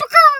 pgs/Assets/Audio/Animal_Impersonations/chicken_2_bwak_04.wav
chicken_2_bwak_04.wav